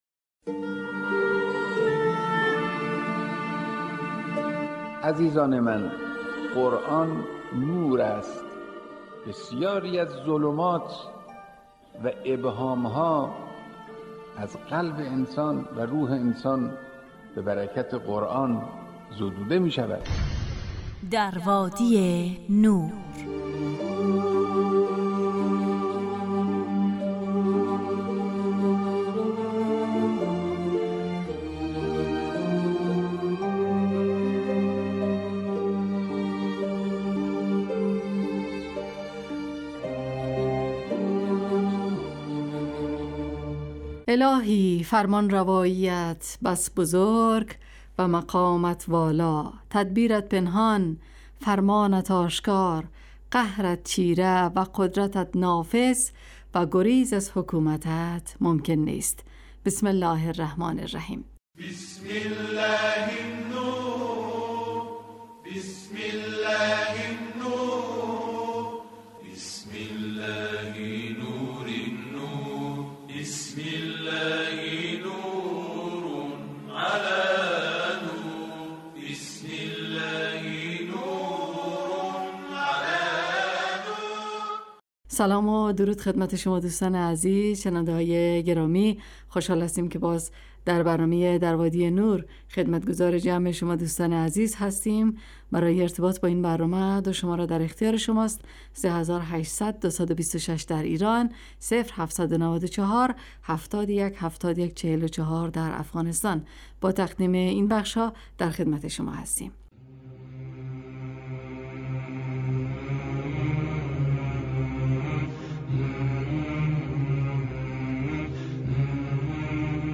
در وادی نور برنامه ای 45 دقیقه ای با موضوعات قرآنی روزهای فرد: ( قرآن و عترت،طلایه داران تلاوت ، دانستنیهای قرآنی، ایستگاه تلاوت، تفسیر روان و آموزه های زن...